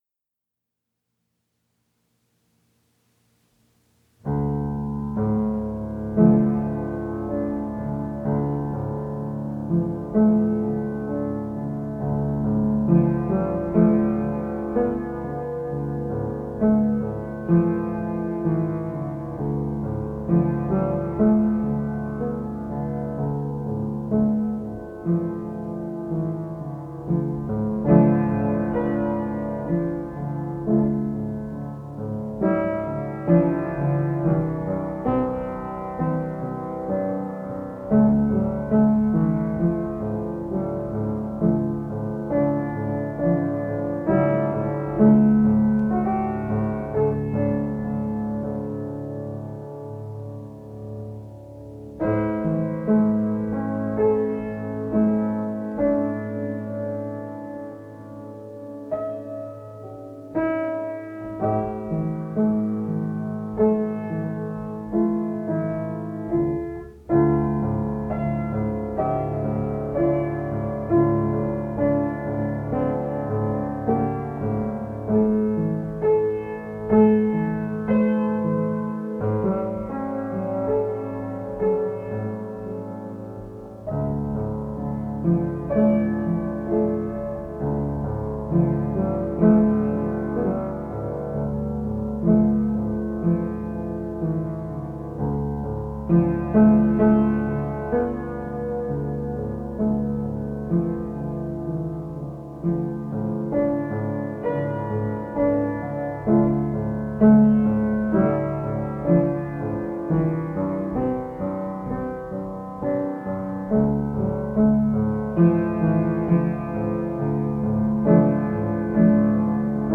“When I See Me”, piano:
When-I-See-Me-piano.mp3